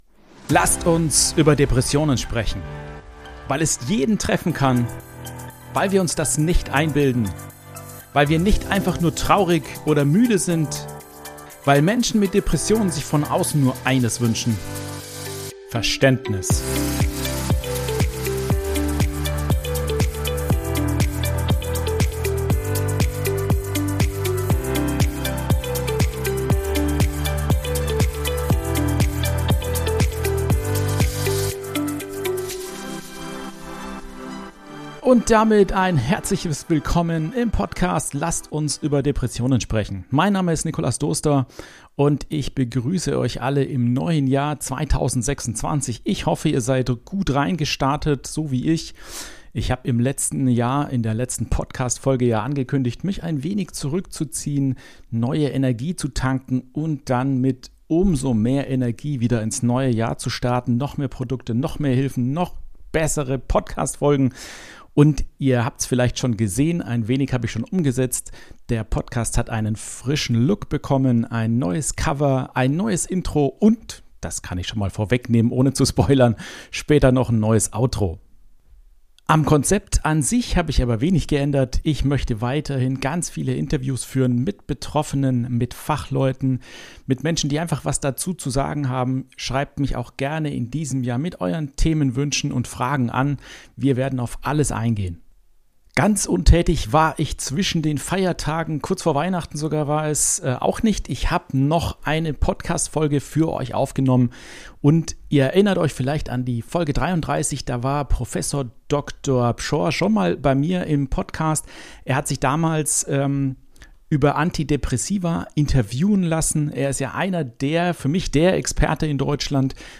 Folge 47 - Antidepressiva absetzen - Interview mit dem Experten